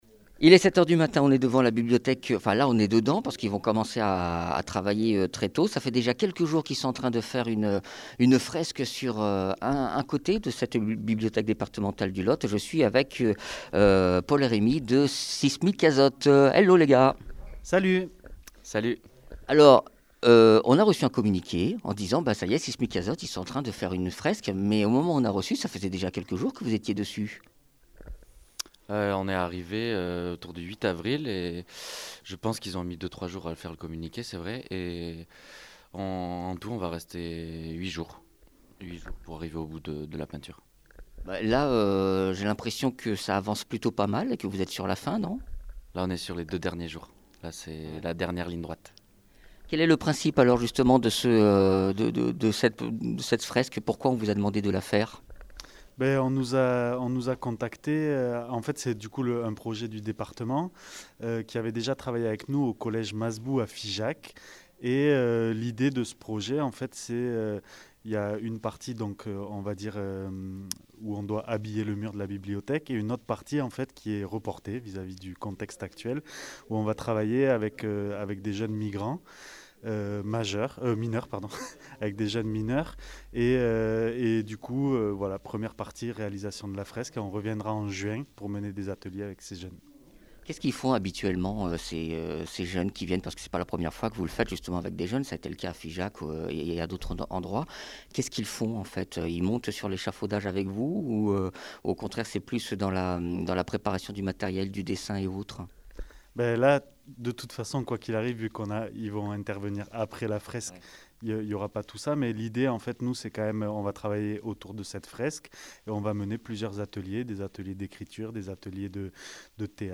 Interviews
Invité(s) : Sismikazot, artistes